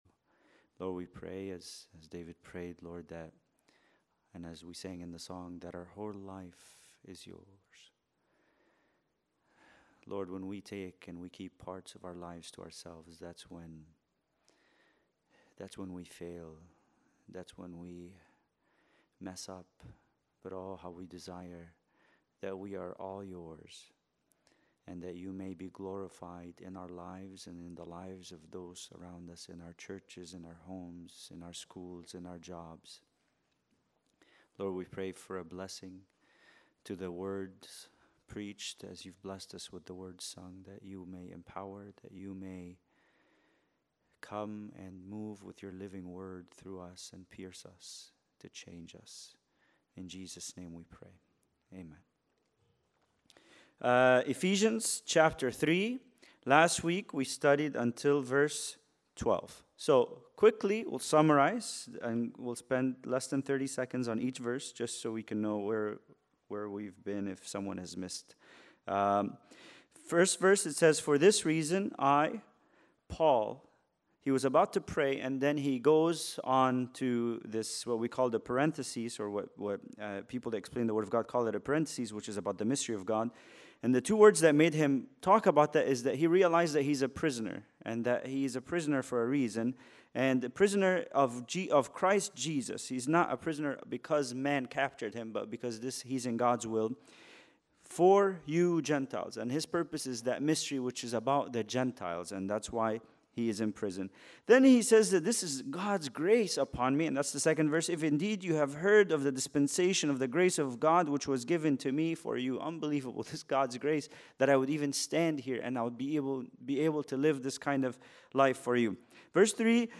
Bible Study: Ephesians 3:13-15